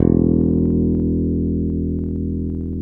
Index of /90_sSampleCDs/Roland LCDP02 Guitar and Bass/BS _Jazz Bass/BS _Jazz Basses